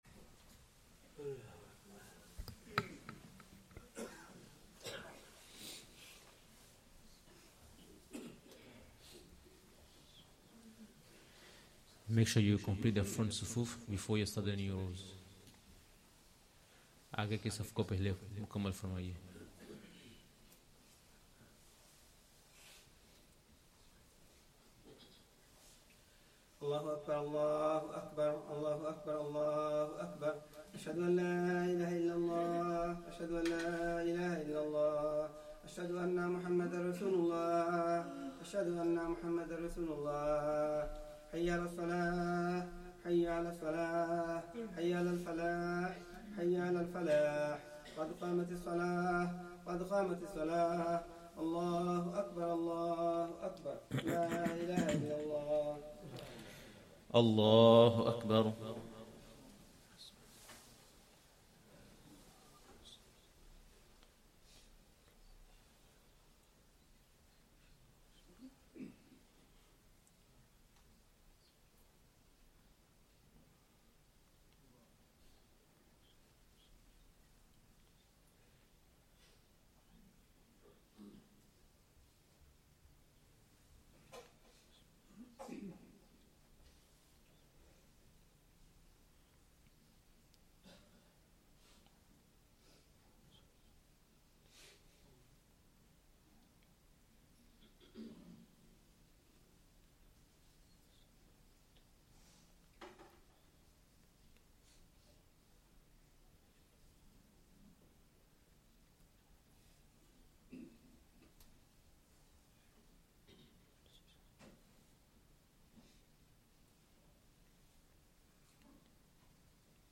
Asr salah + Dars of Quran